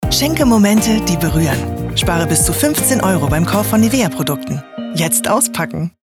sehr variabel, dunkel, sonor, souverän
Deutsch - warm, fröhlich, motivierend
Commercial (Werbung)